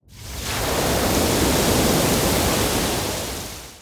Free Fantasy SFX Pack
SFX / Spells / Wave Attack 1.ogg
Wave Attack 1.ogg